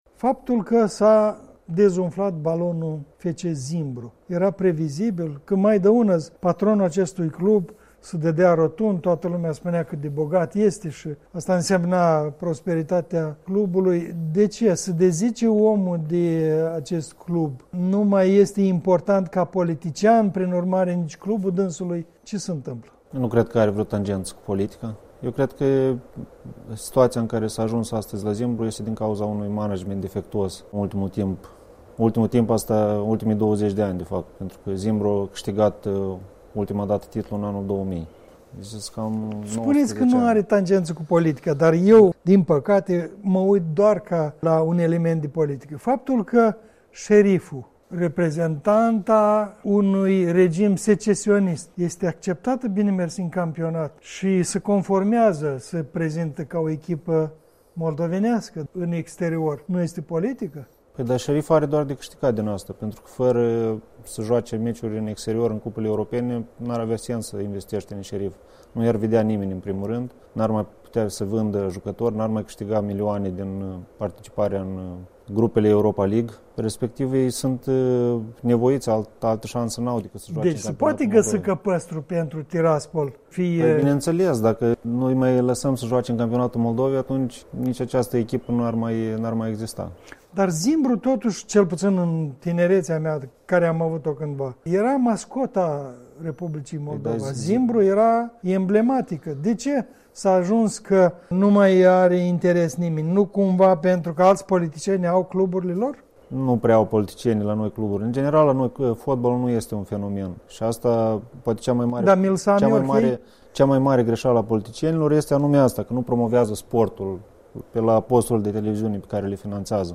Interviu cu un jurnalist sportiv despre legăturile politicienilor cu fotbalul.